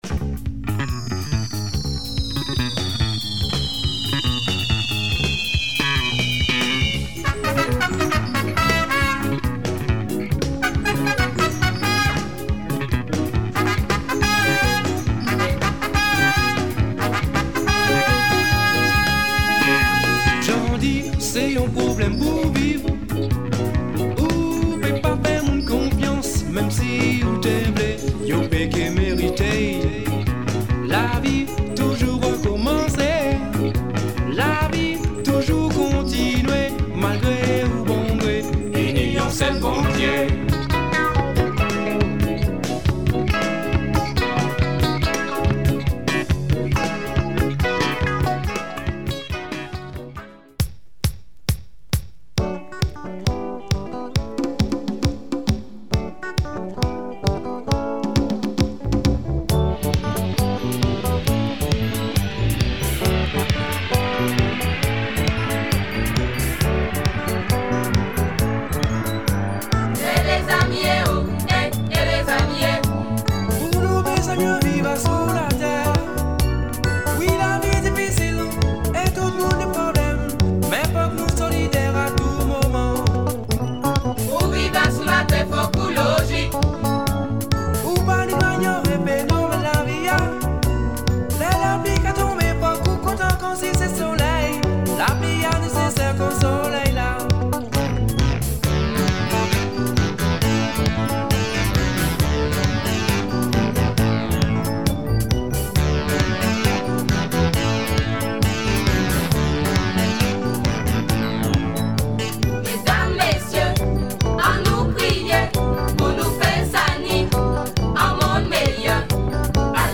afro soca
afro compas
Killer groove !